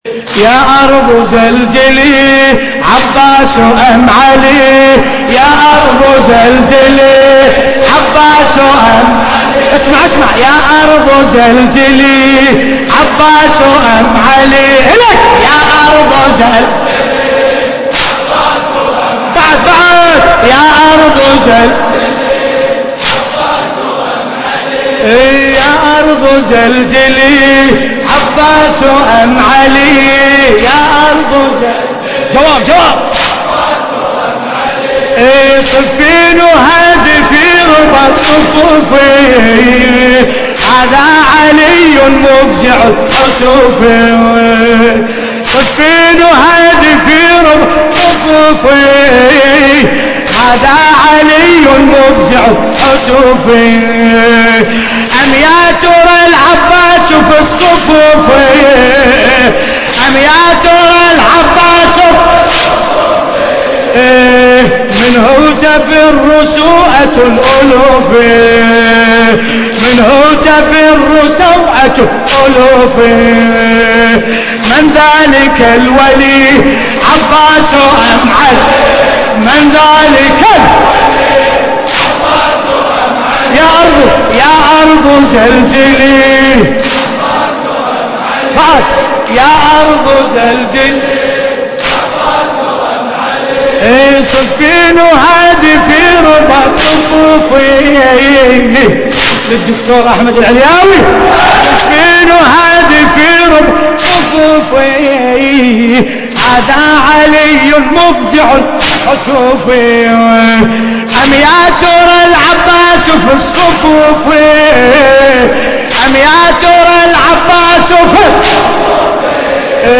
القارئ: باسم الكربلائي التاريخ: الليلة السابع من شهر محرم الحرام عام 1434 هـ - الكويت .